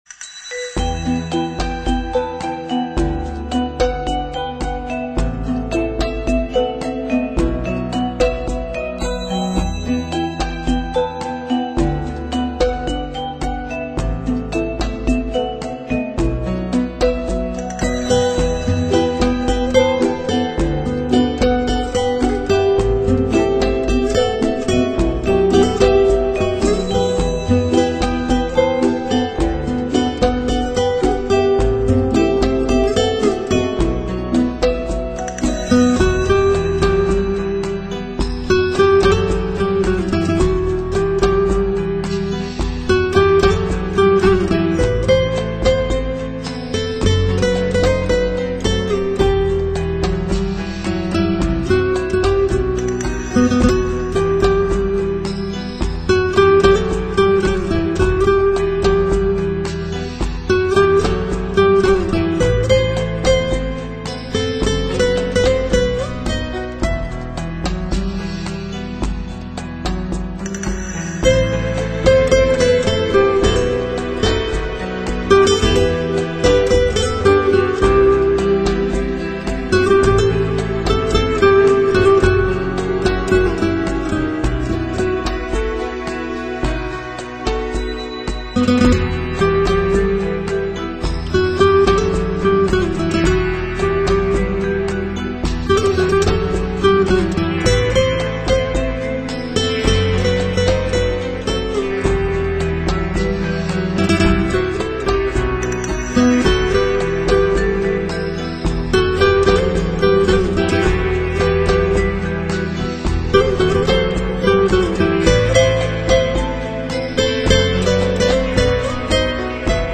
华丽的 吉他乐章，丰富的音乐元素，充满想象的创造力